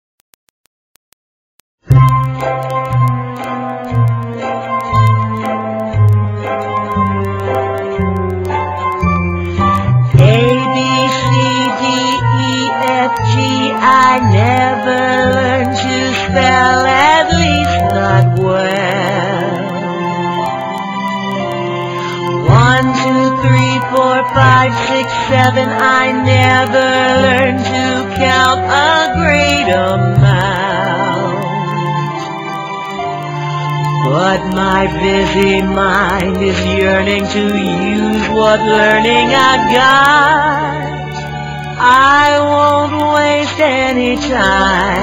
NOTE: Vocal Tracks 1 Thru 11